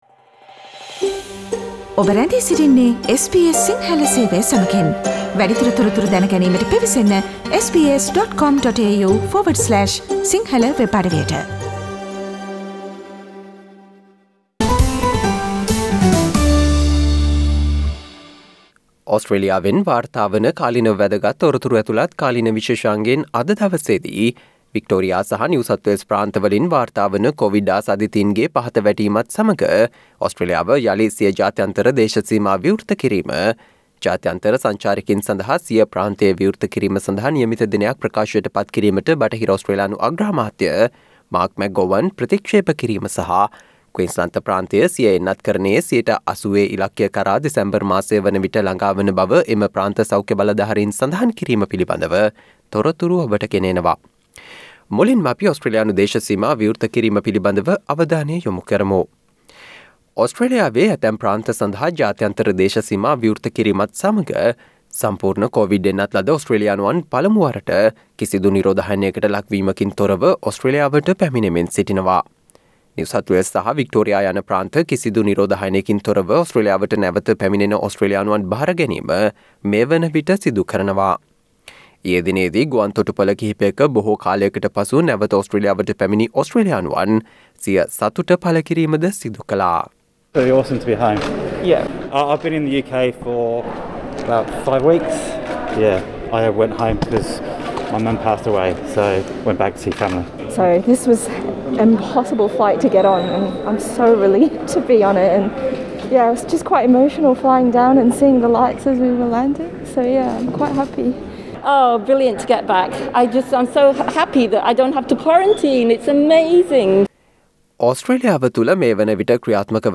Listen to the SBS Sinhala feature with the latest information on COVID-19, reported from Australia